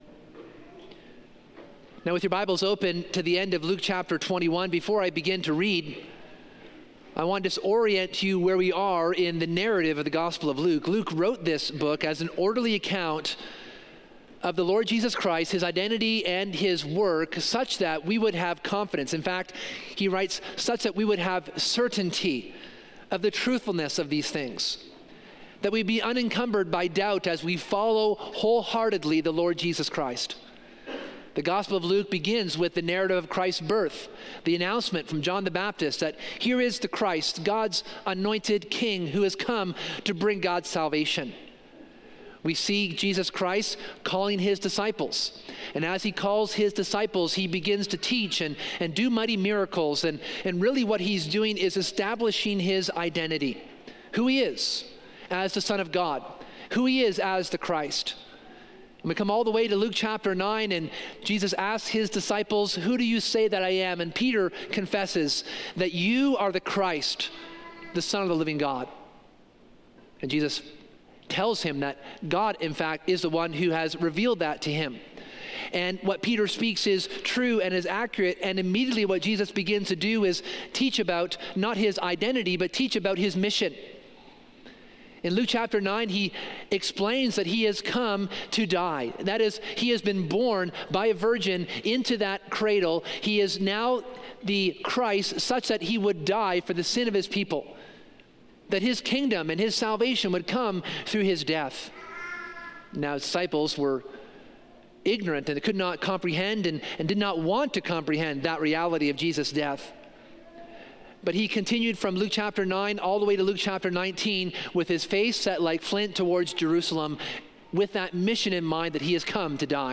In this sermon, we return to the main narrative in the gospel of Luke. Jesus has come to give his life to inaugurate the new covenant and the kingdom of God.